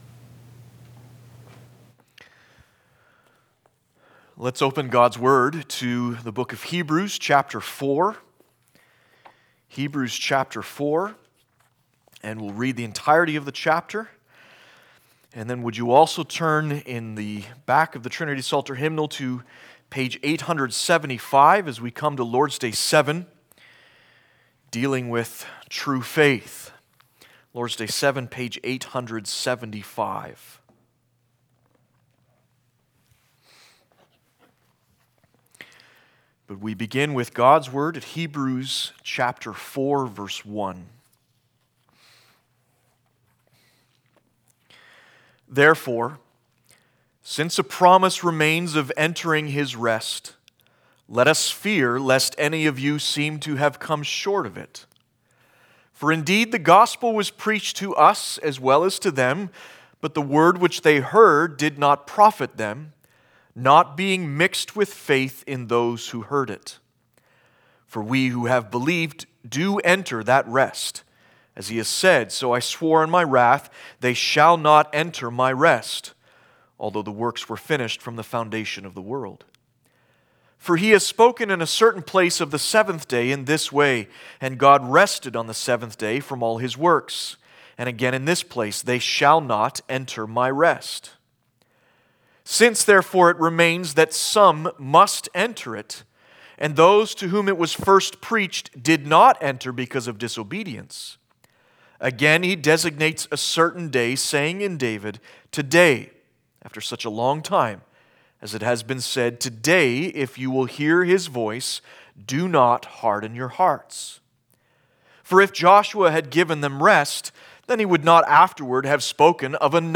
Series: Heidelberg Catechism Passage: Hebrews 4 Service Type: Sunday Afternoon